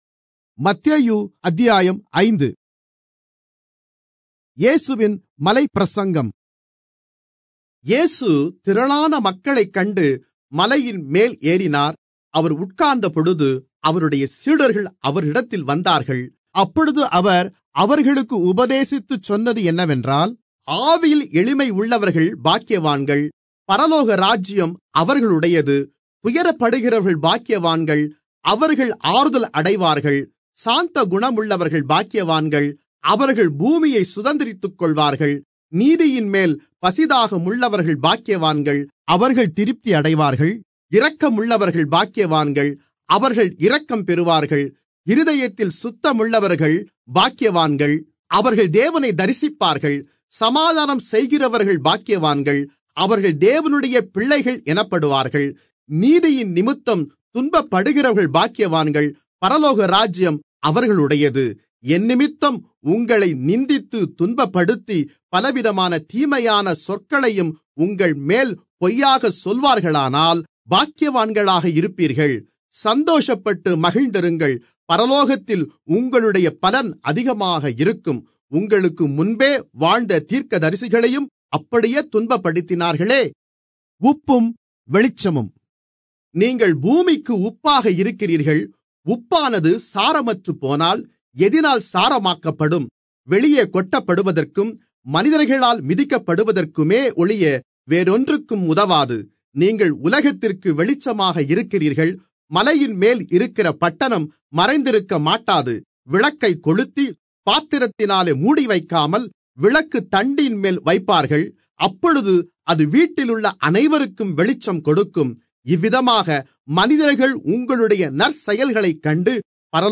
Tamil Audio Bible - Matthew 16 in Irvta bible version